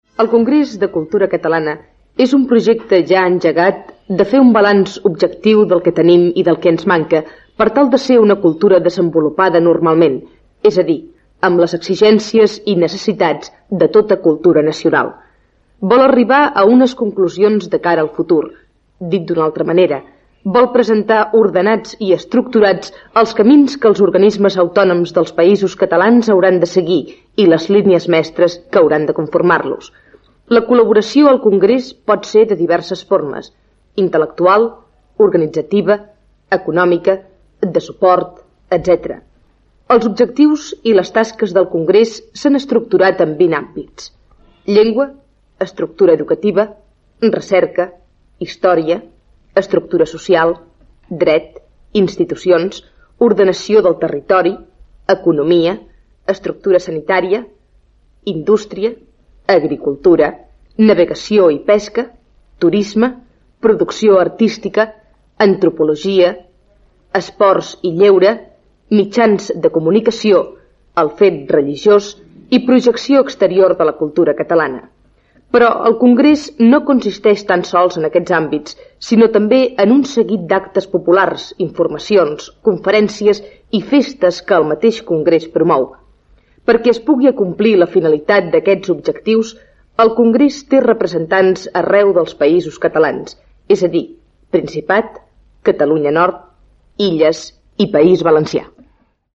Antena 5: El Congrés de Cultura Catalana - coproducció de 5 emissores de Barcelona, 1976